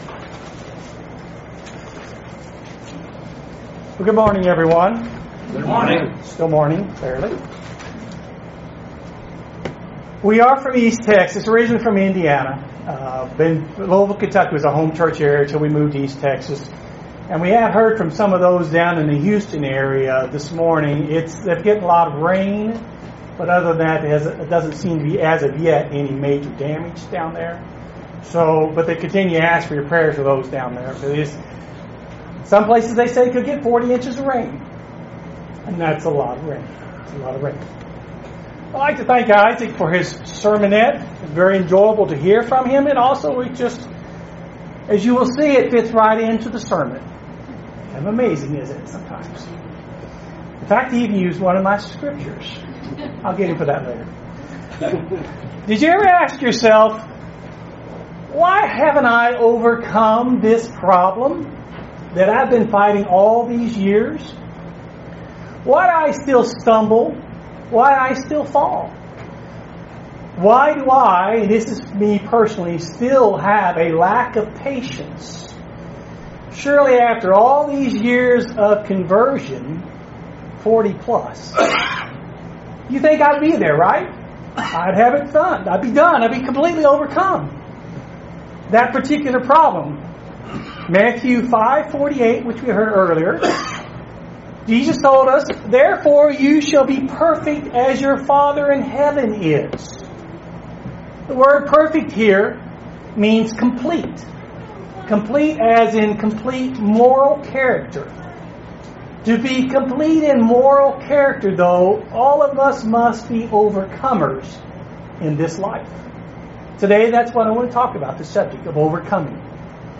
Excellent Sermon on overcoming sin in our lives. the 3 things we have to overcome to remove sin from our lives.
Given in Lexington, KY